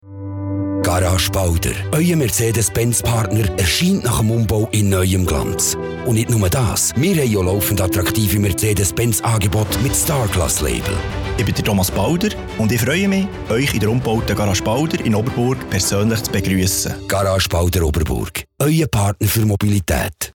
Werbung Schweizerdeutsch (BE) Hörprobe 03